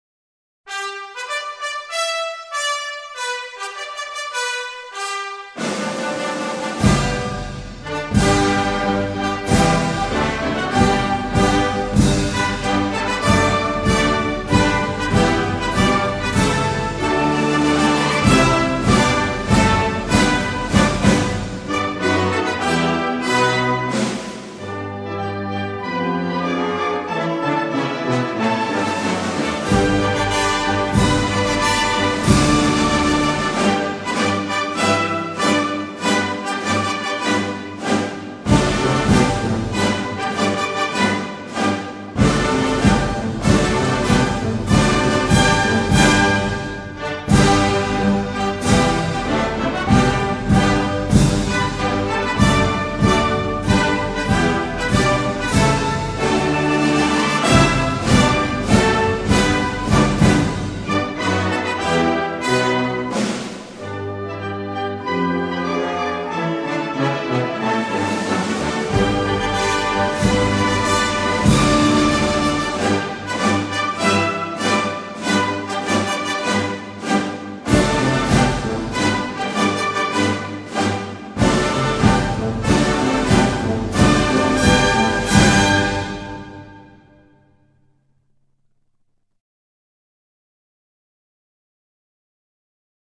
附：国歌伴奏曲